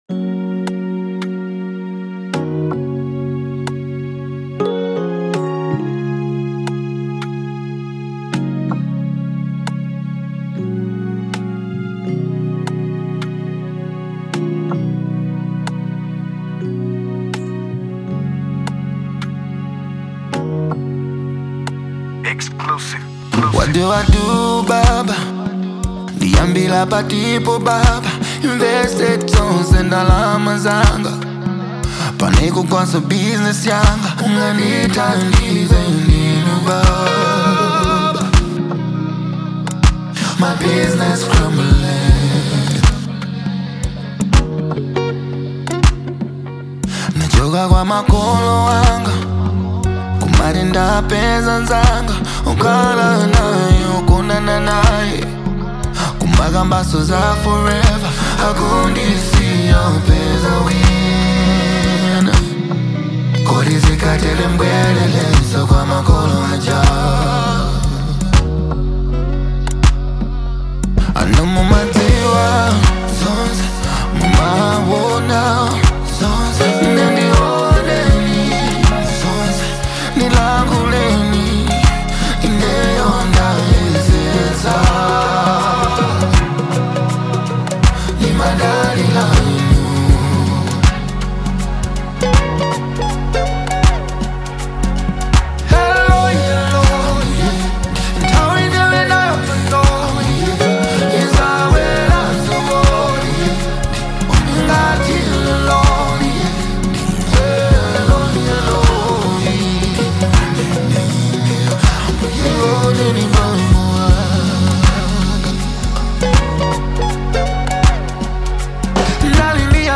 Genre : RnB